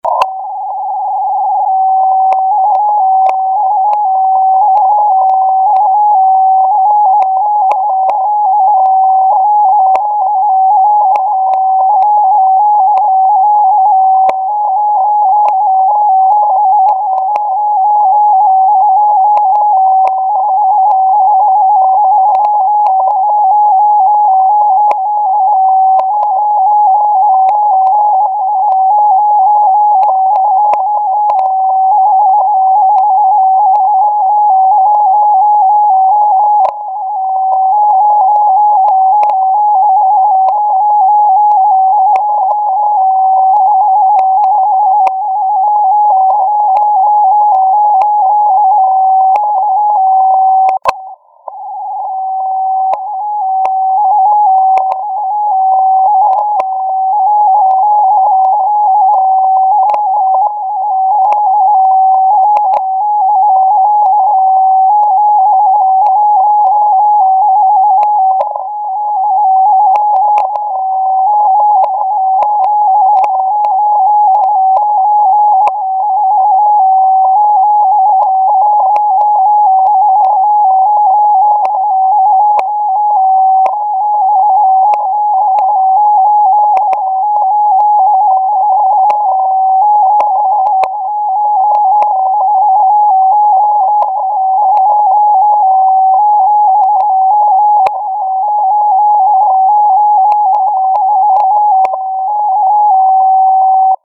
Émissions télégraphiques exotiques